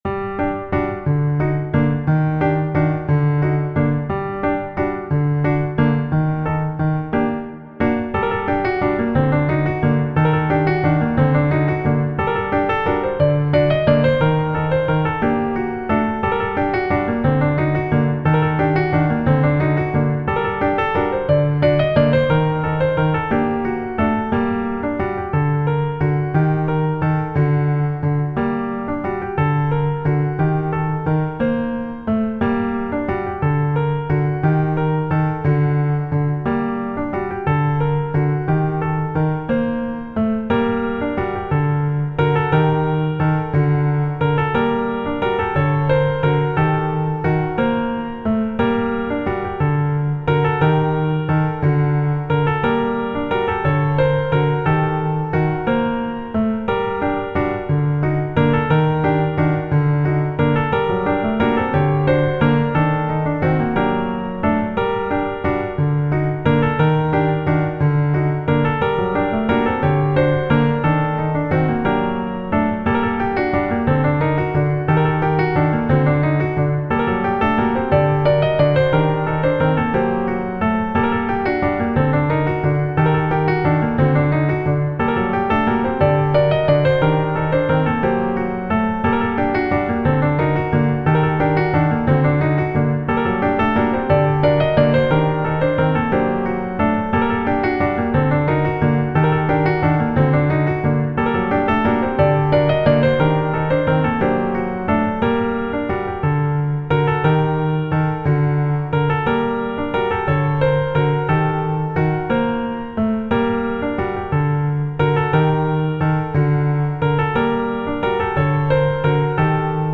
オルゴール楽曲として書いた楽曲のピアノアレンジバージョン。
本楽曲では伴奏パートのスタッカートがルーズになってしまった点が1番影響が大きく、調整を加えている。